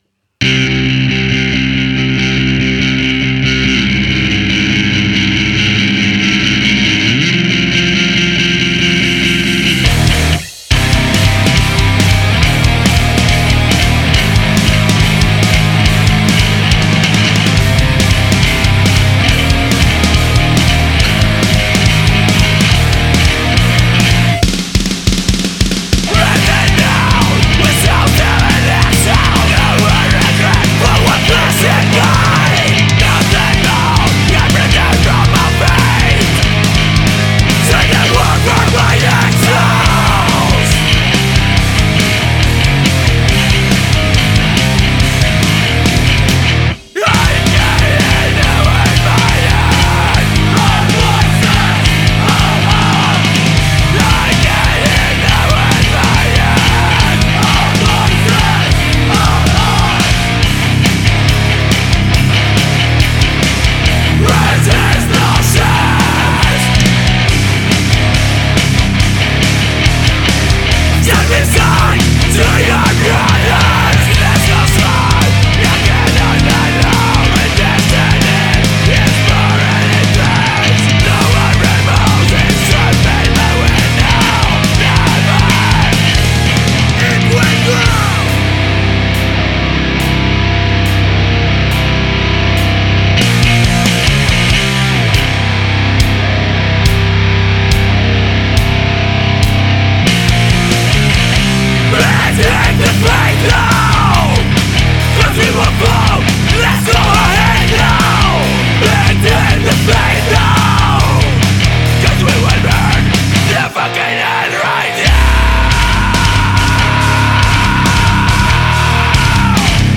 Bolzano Hardcore